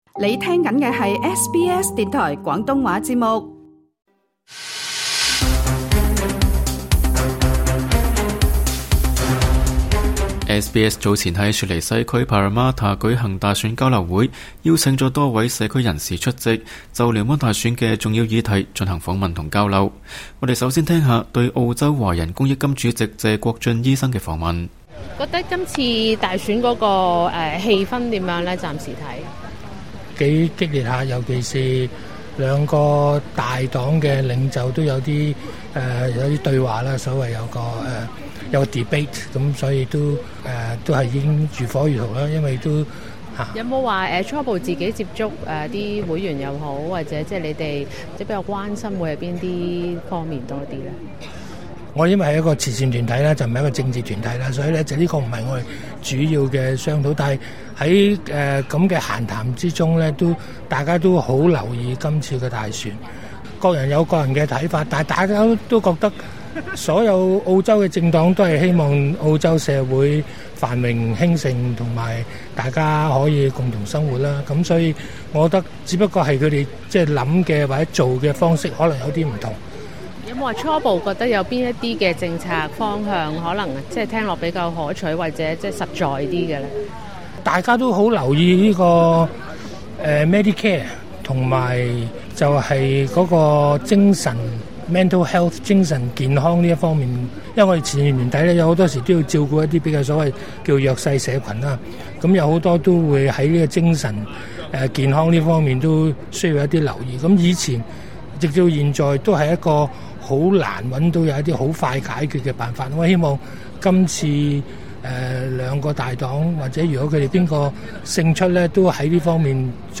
聯邦大選在即，SBS早前在雪梨西區Parramatta舉行大選交流會，邀請了多位華人社區代表出席，就聯邦大選的重要議題進行訪問和交流。